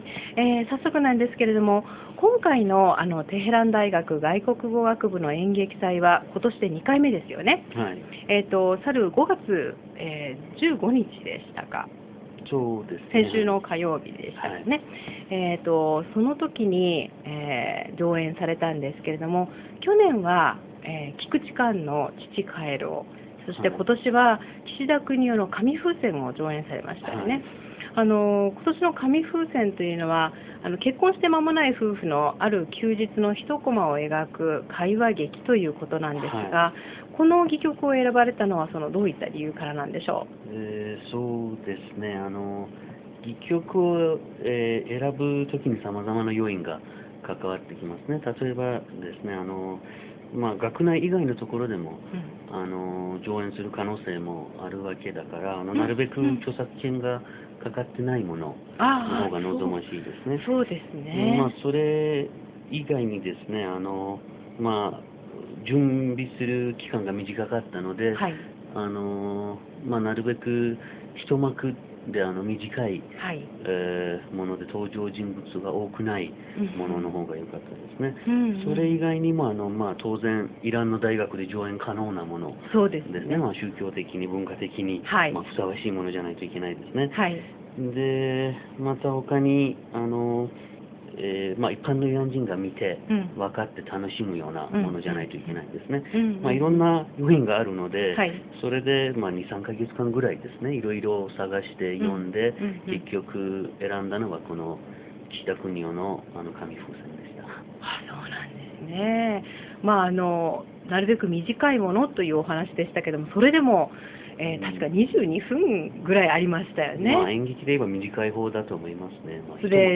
テヘラン大学外国語学部演劇祭でのインタビュー（２）